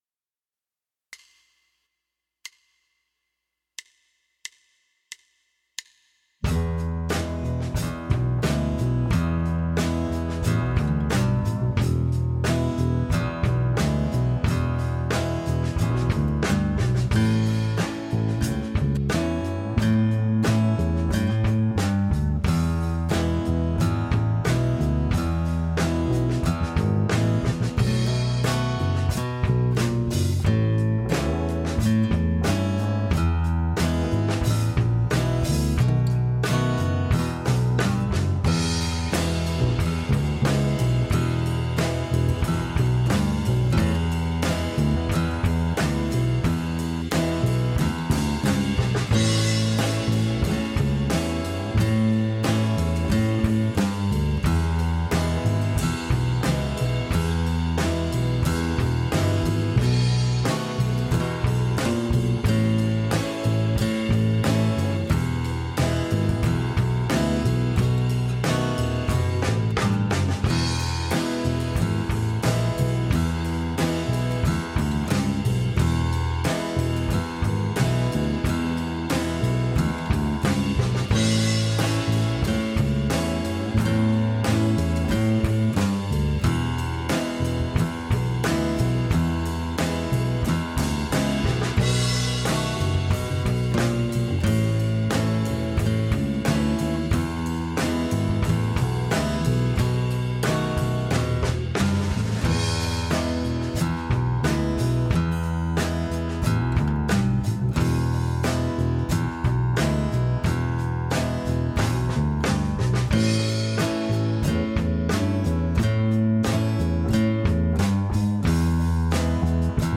Key: E